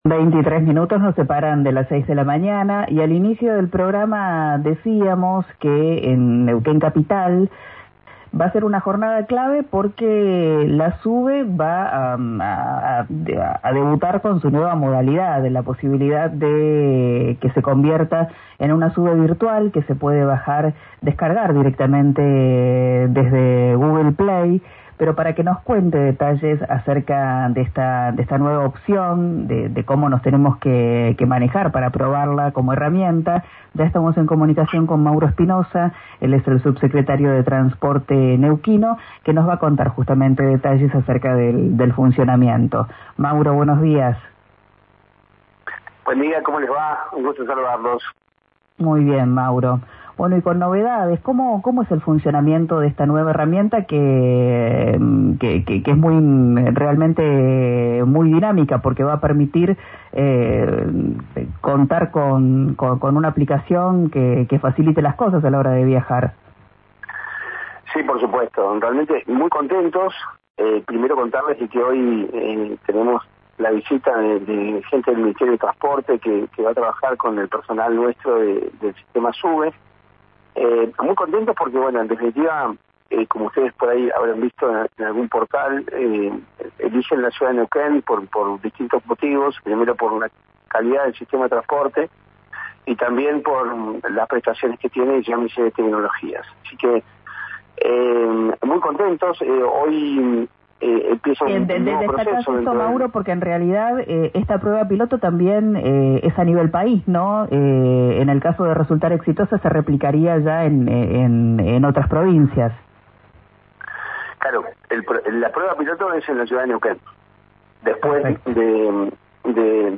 En diálogo con “Arranquemos” en RÍO NEGRO RADIO, detalló que una de las novedades es la carga a bordo, que permite a los usuarios que puedan cargar la tarjeta de manera virtual arriba del colectivo.